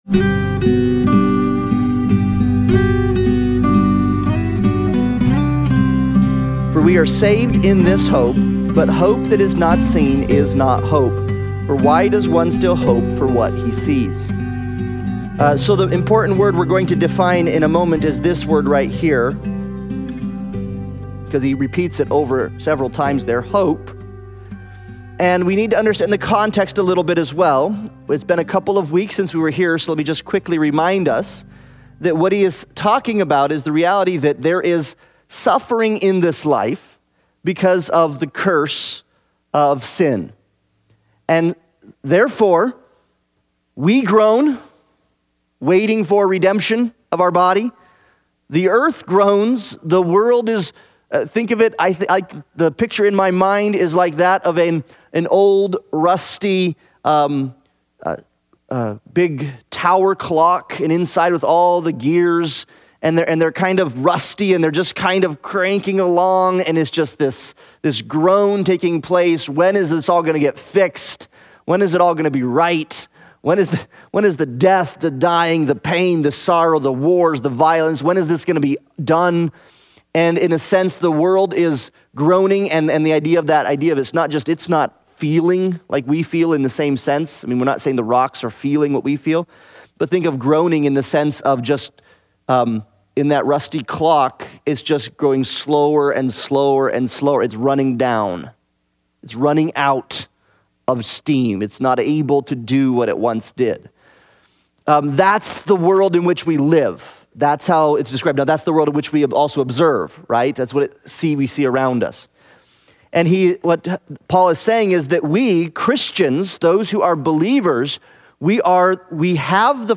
Romans Analysis Passage: Romans 8:23-28 Service Type: Sunday Bible Study « The Son Gives Life